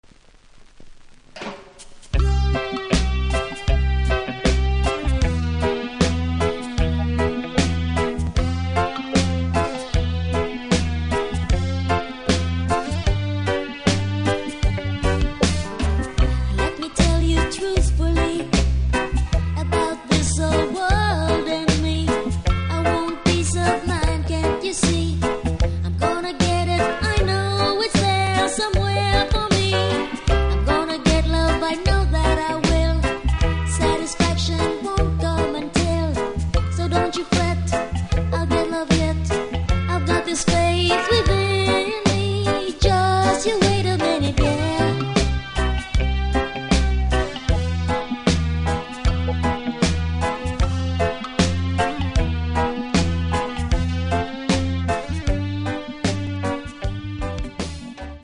両面良いのですがセンターずれあり音に影響あります